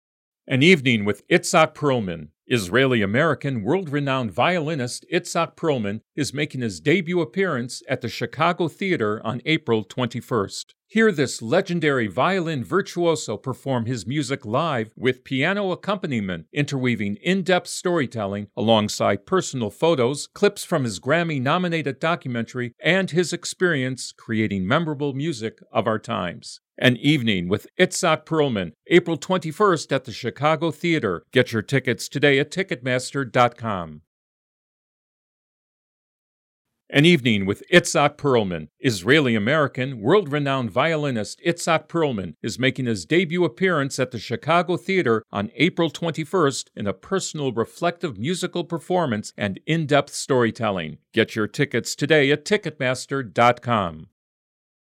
English (American)
Commercial, Deep, Senior, Mature, Friendly
Explainer